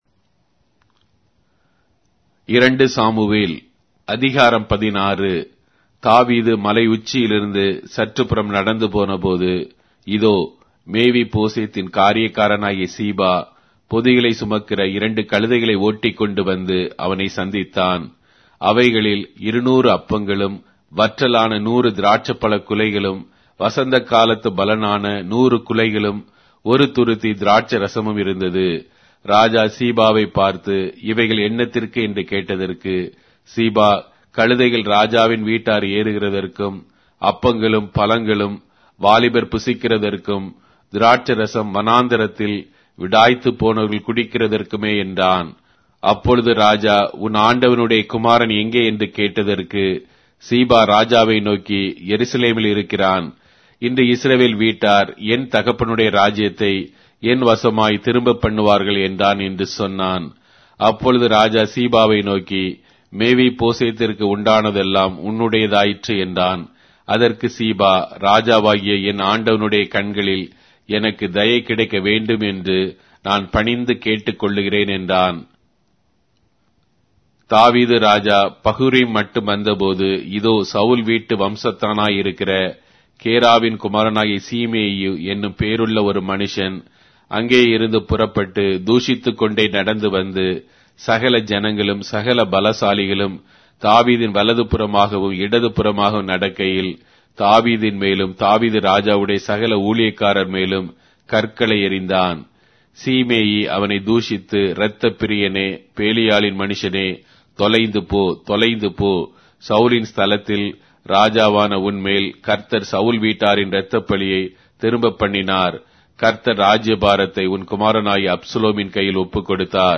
Tamil Audio Bible - 2-Samuel 17 in Ervpa bible version